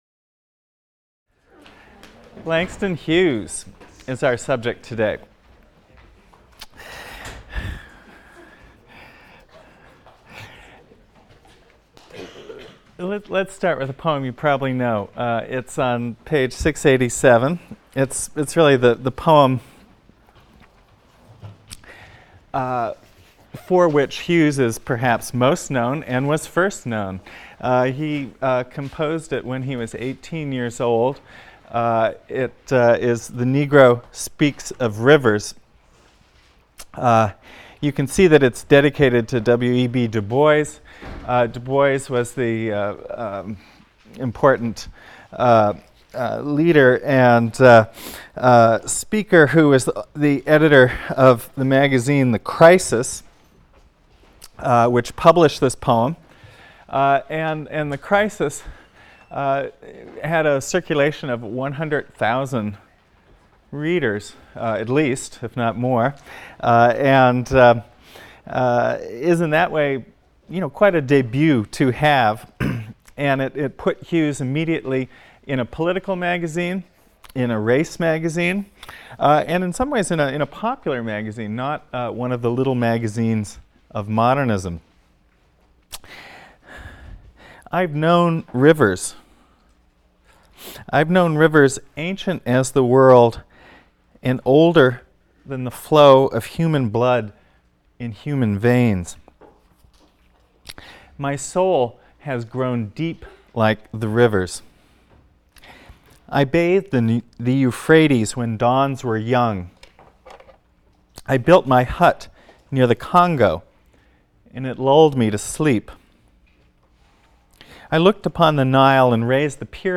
ENGL 310 - Lecture 15 - Langston Hughes | Open Yale Courses